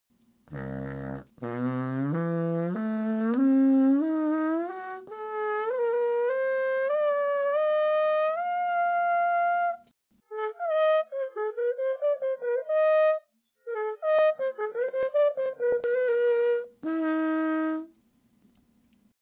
Let its mellow tones carry you away...